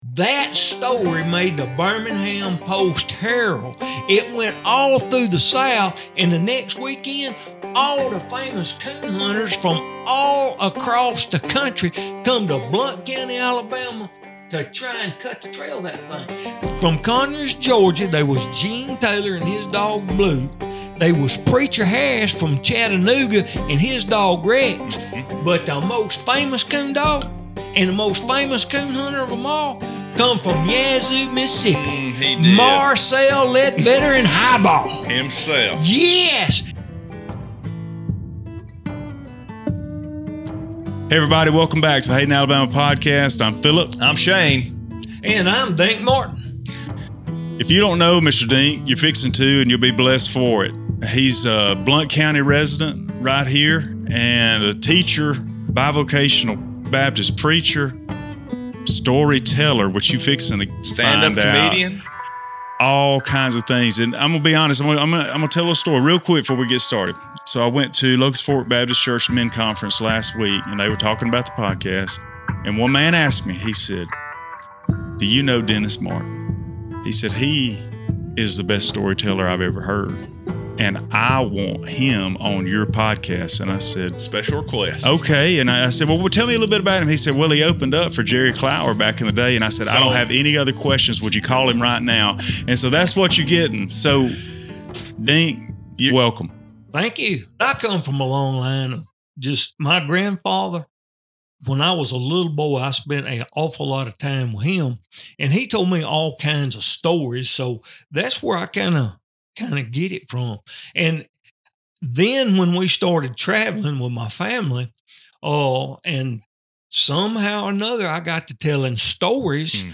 Opening for a Legend | Southern Storytelling | Episode 91